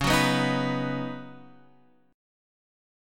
C#9sus4 chord